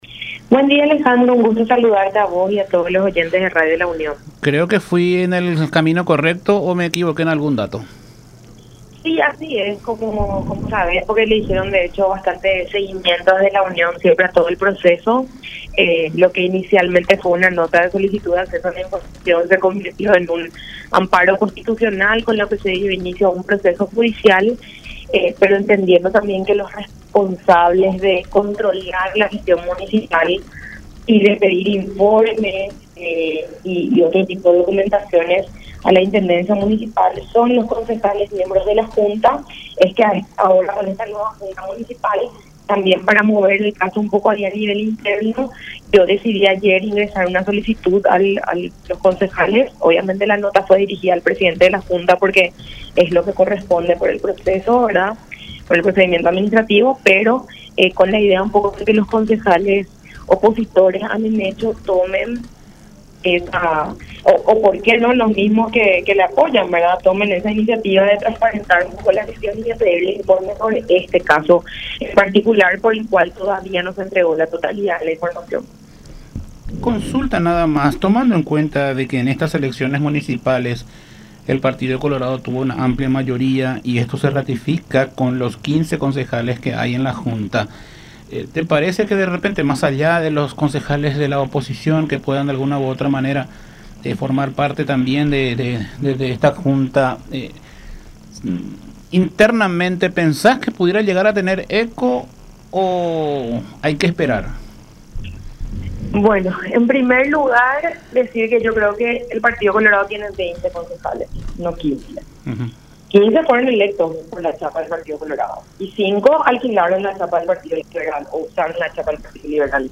en charla con Todas Las Voces por La Unión